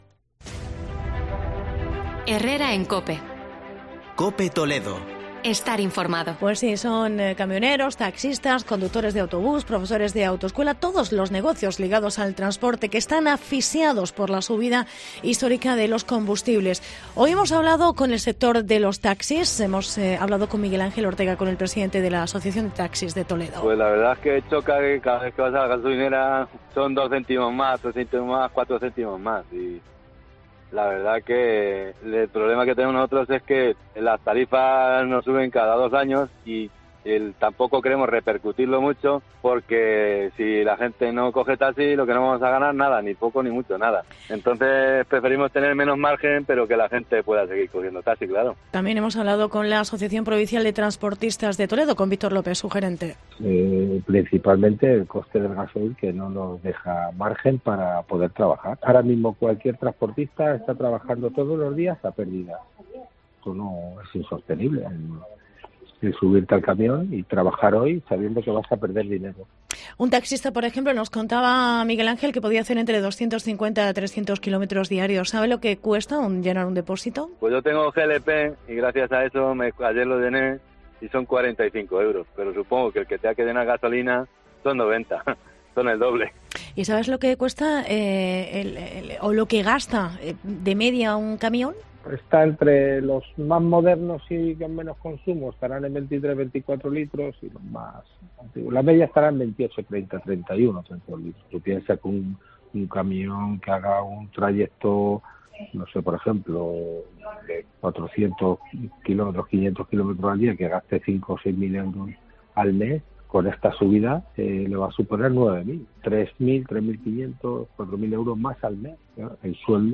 Reportaje precio de la gasolina, taxistas y transportistas